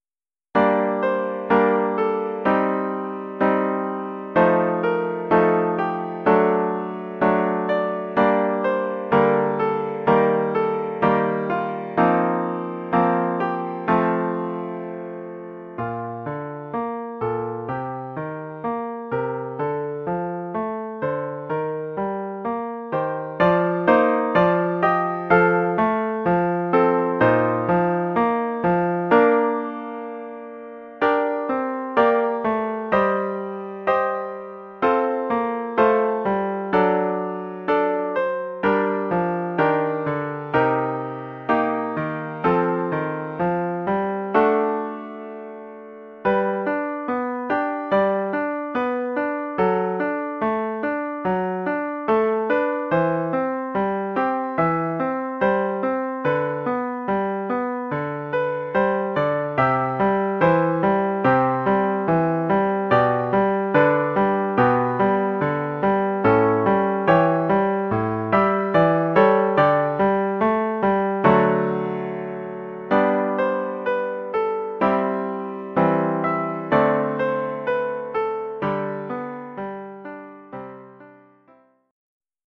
Collection : Piano
Oeuvre pour piano solo.